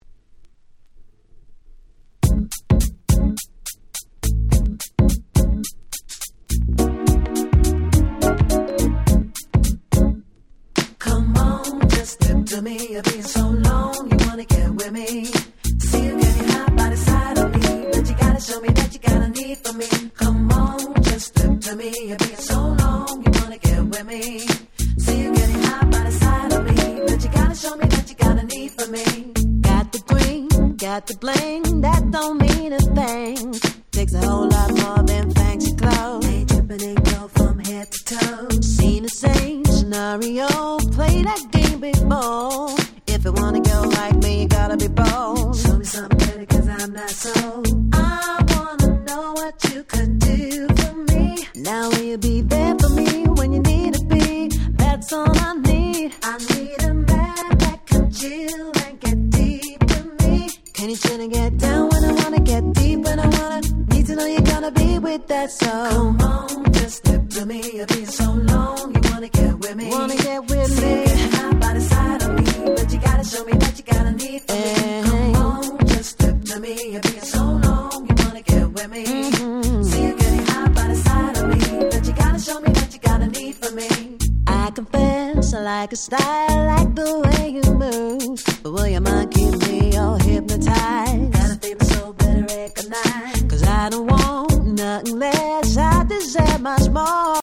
02' Nice UK R&B !!
大人な良質Hip Hop Soulな